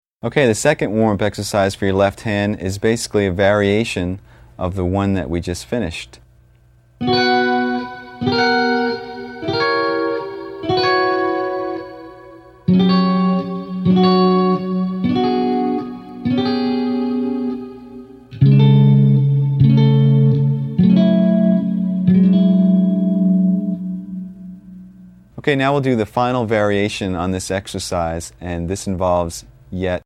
Voicing: Guitar Method